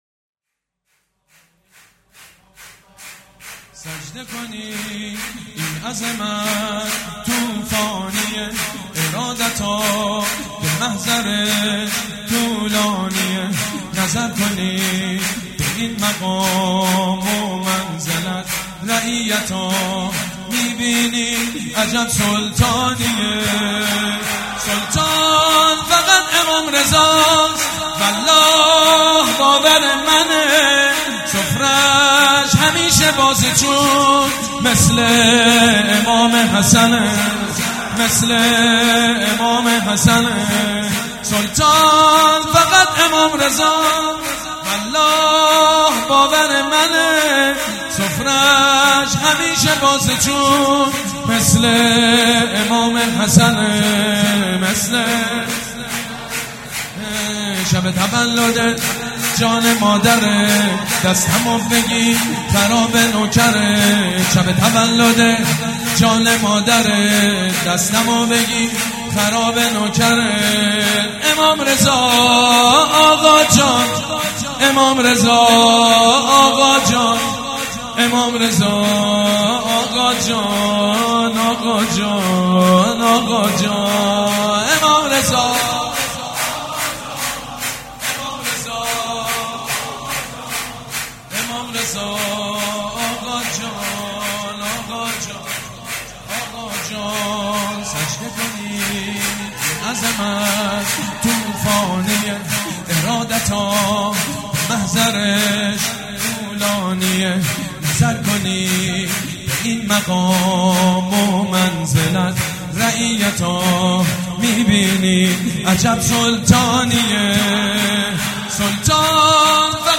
«میلاد امام رضا 1397» شور: سجده کنید این عظمت طوفانی
«میلاد امام رضا 1397» شور: سجده کنید این عظمت طوفانی خطیب: سید مجید بنی فاطمه مدت زمان: 00:05:47